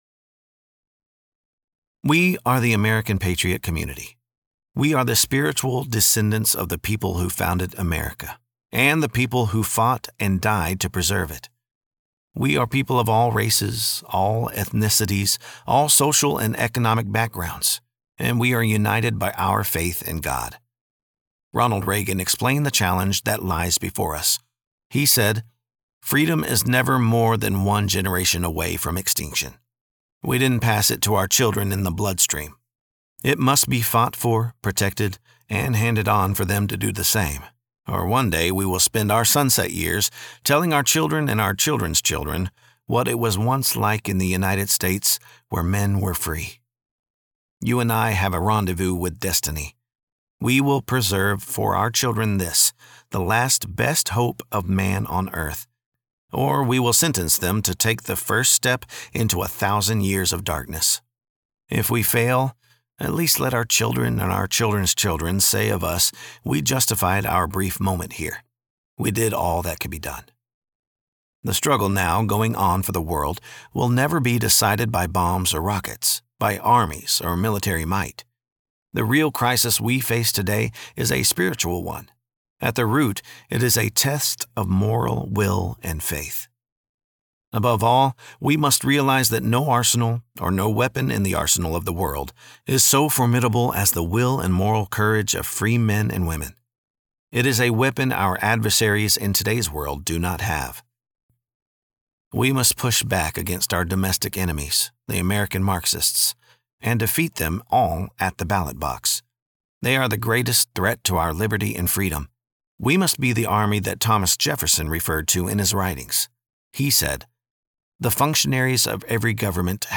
Audiobook-Sample.mp3